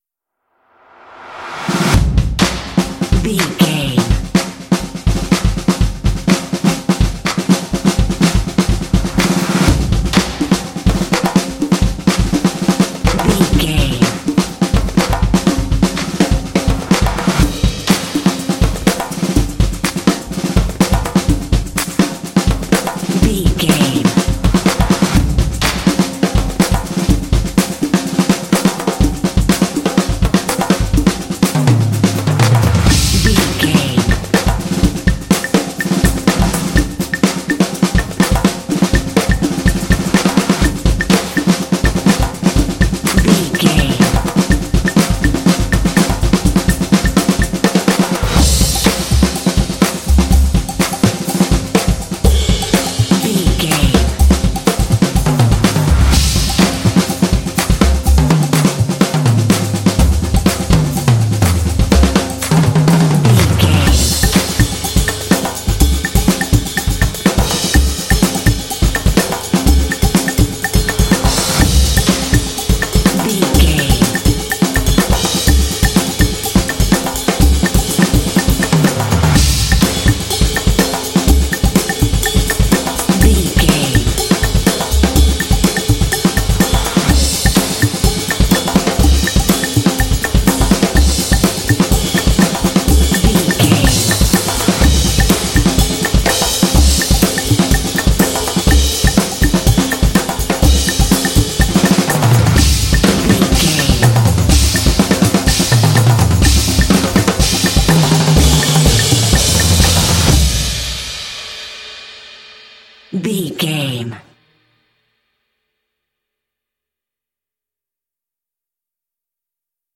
This epic drumline will pump you up for some intense action.
Epic / Action
Atonal
driving
determined
drumline